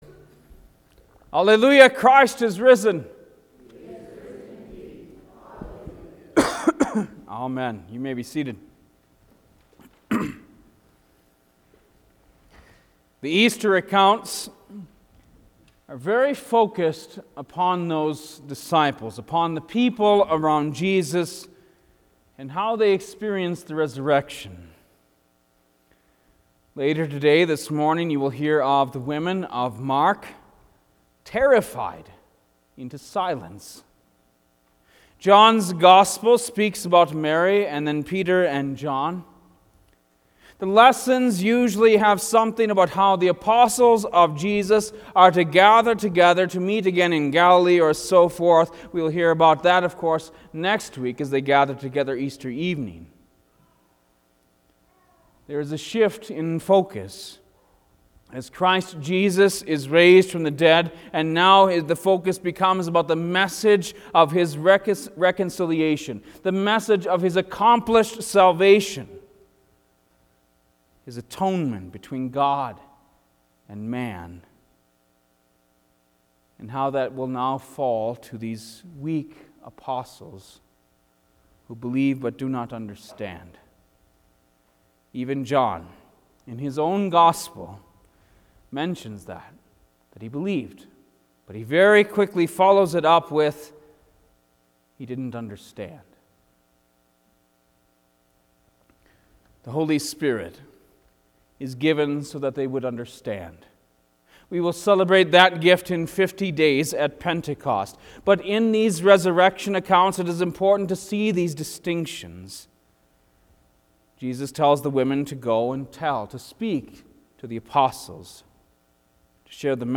Easter Sunrise